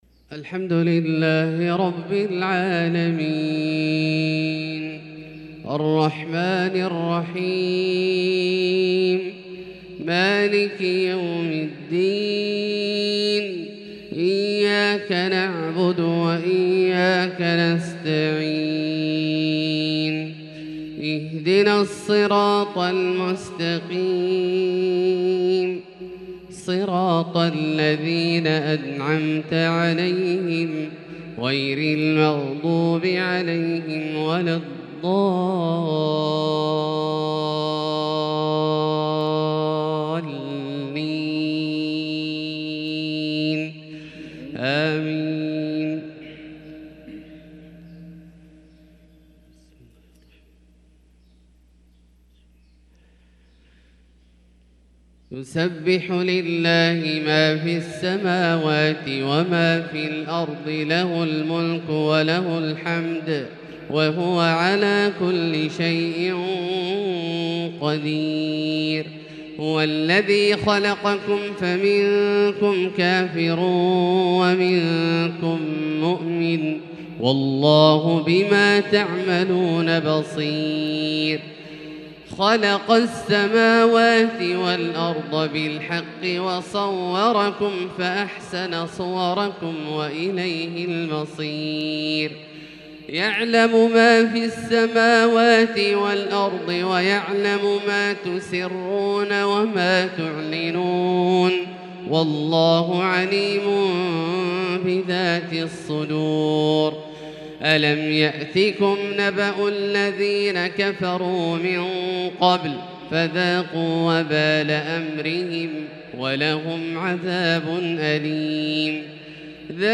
فجر الأحد 5-7-1443هـ سورة التغابن كاملة | Fajr prayer from surat At-Taghabun 6-2-2022 > 1443 🕋 > الفروض - تلاوات الحرمين